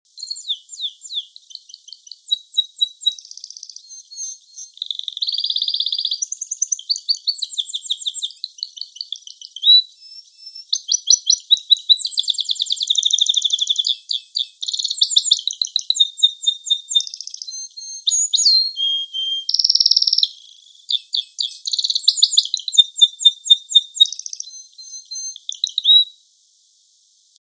canarios de canto
timbrado_filtrado_curto.mp3